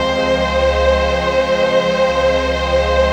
DM PAD2-43.wav